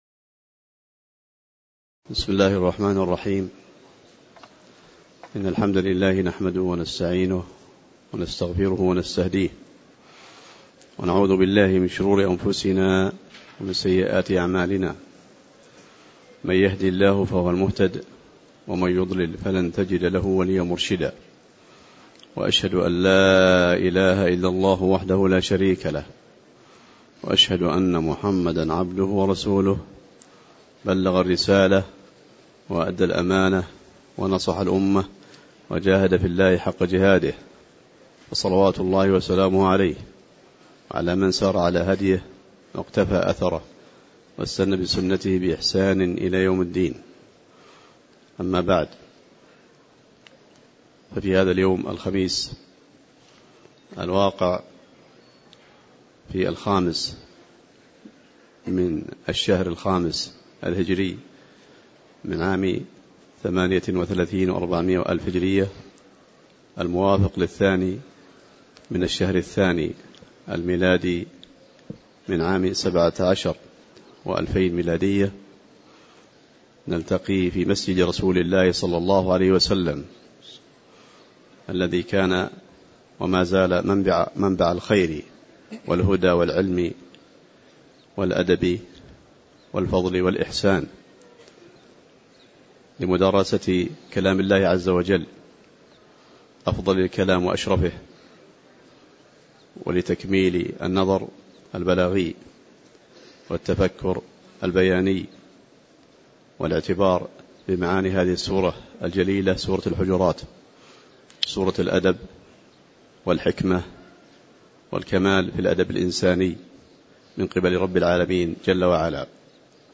تاريخ النشر ٥ جمادى الأولى ١٤٣٨ هـ المكان: المسجد النبوي الشيخ